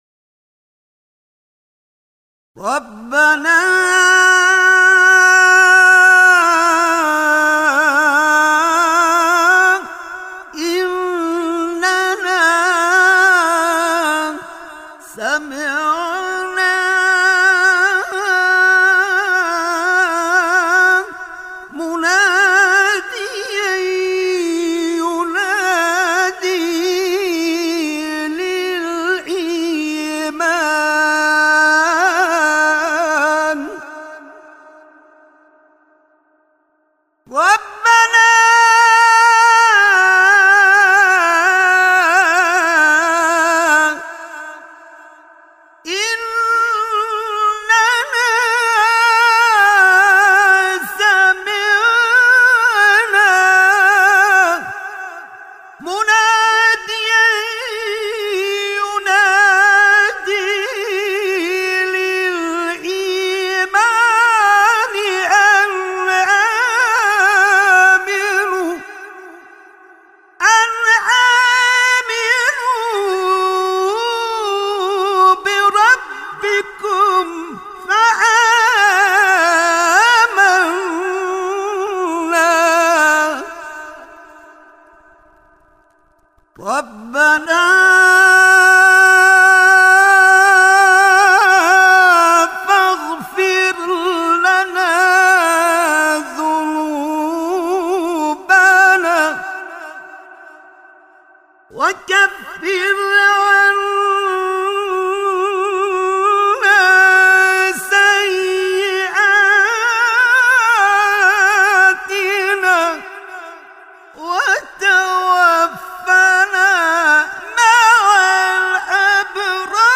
قاری پیشکسوت کشورمان
فرازهای اول بداهه‌خوانی در مایه سه‌گاه